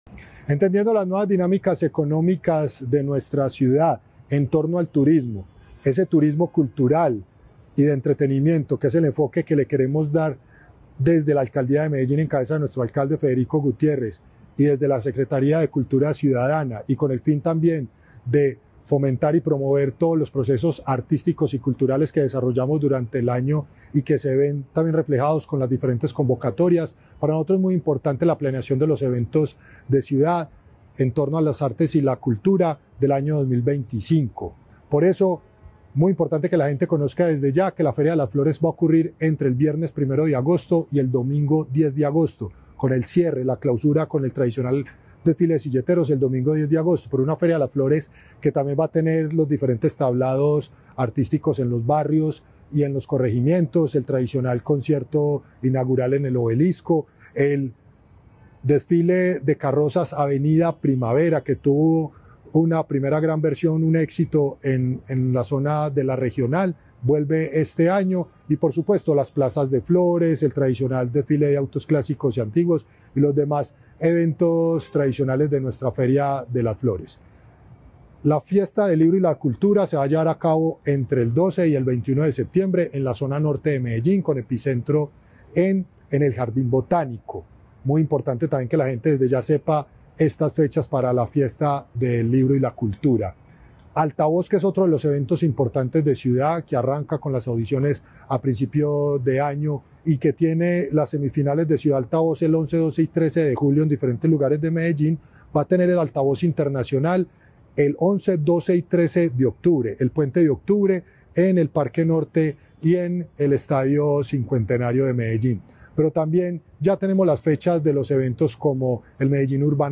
Palabras de Cristian Cartagena, subsecretario de Arte y Cultura La capital antioqueña será escenario de variada programación cultural y artística promovida por la Administración Distrital.